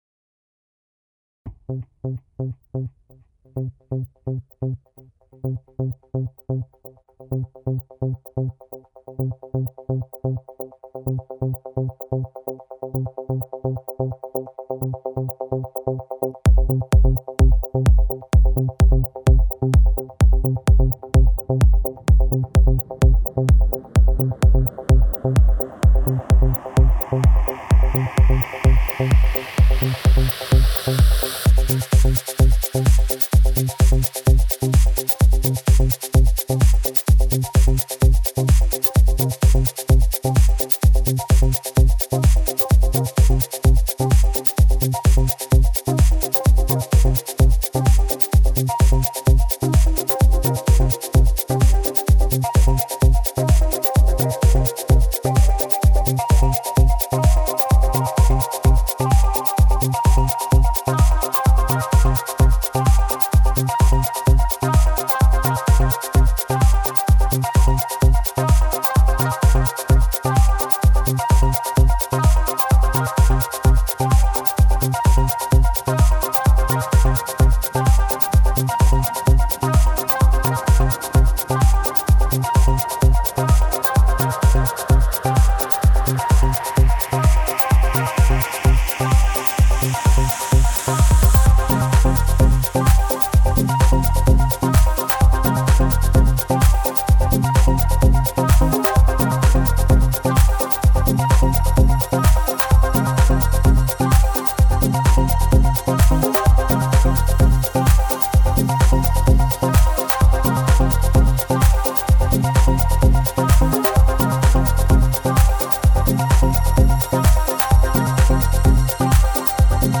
Minimal, Electronic, Jazz, Tech, Vintage, Oldschool - House